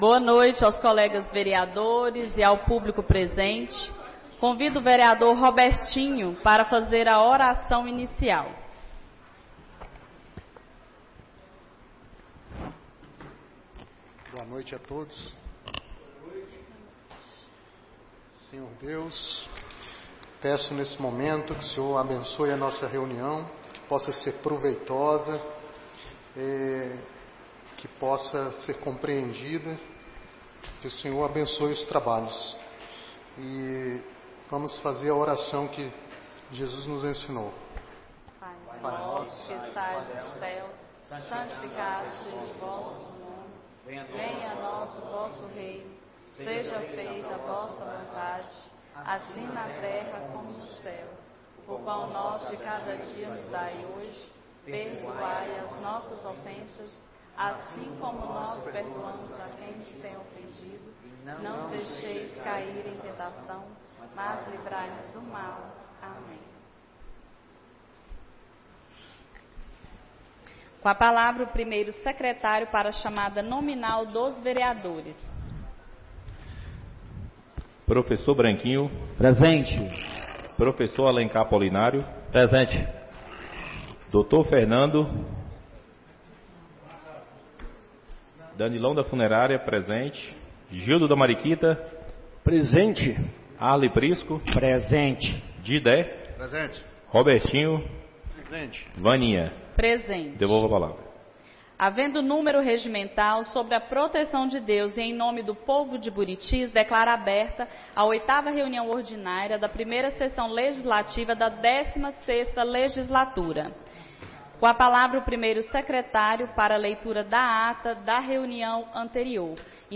8ª Reunião Ordinária da 1ª Sessão Legislativa da 16ª Legislatura - 10-03-25